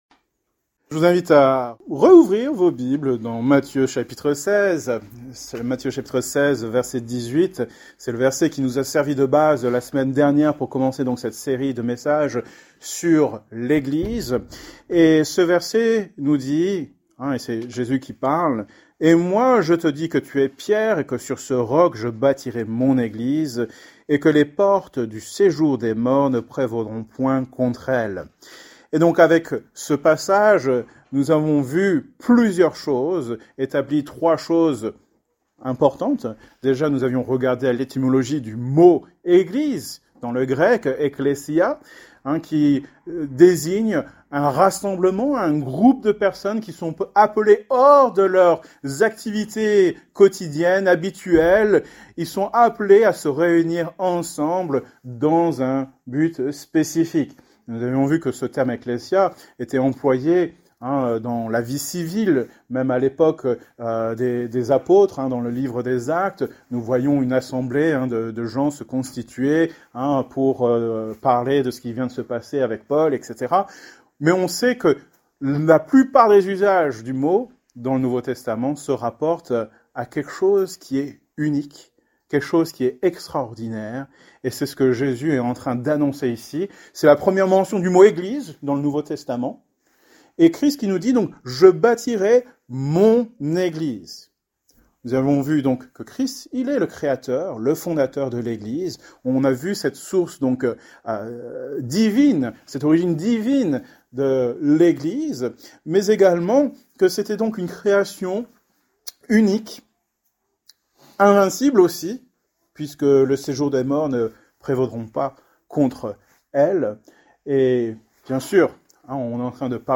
Genre: Prédication